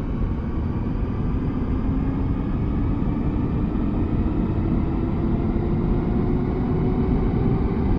enginestart.ogg